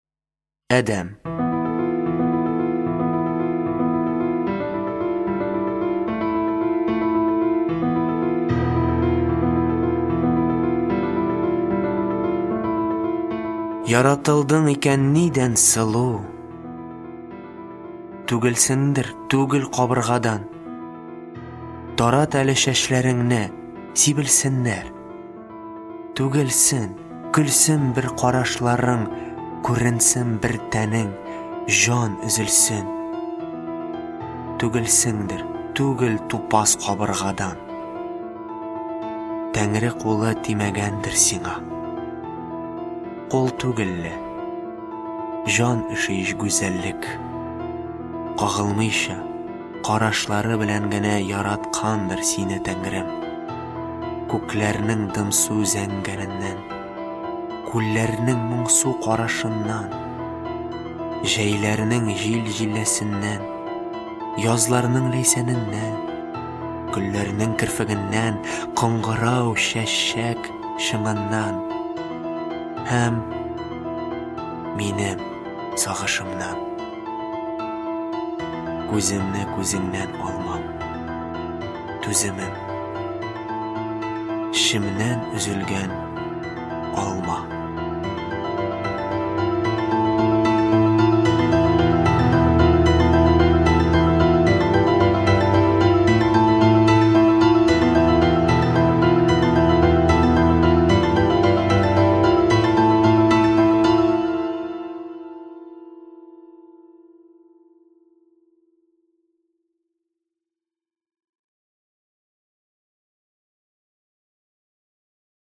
Аудиокнига Шигырьләр | Библиотека аудиокниг